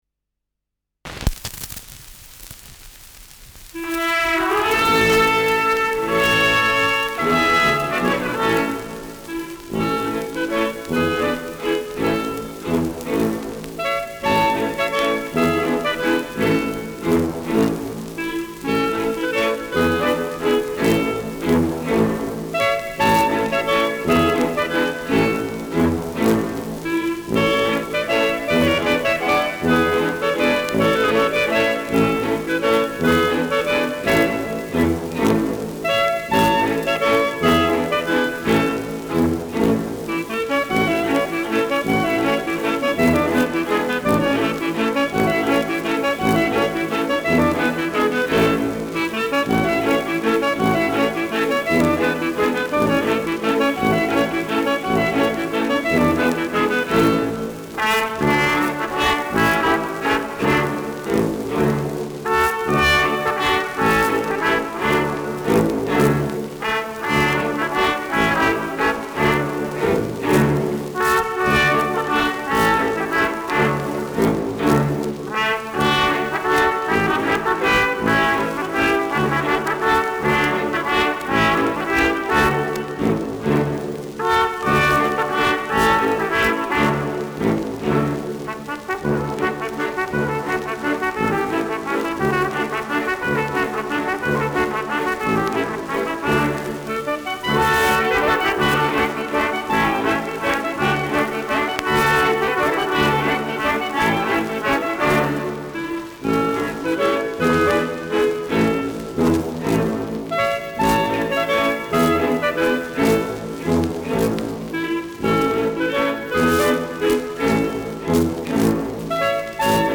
Schellackplatte
Leichtes Grundrauschen : Gelegentlich leichtes Knacken und Zischen : Gegen Ende hoher Pfeifton
Dachauer Bauernkapelle (Interpretation)
[München] (Aufnahmeort)